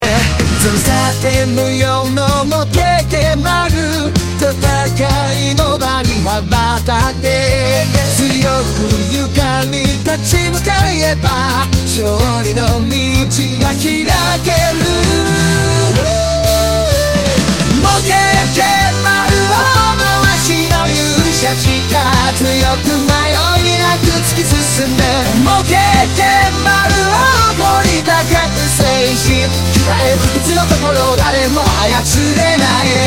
児童向けアニメっぽくアガる曲調やね🥺
平成中期の夕方アニメ感
「もけーけー」のリズムが気持ちいい??
ザ・ゴールデンタイム枠のアニメOPって感じがめっちゃ好きだから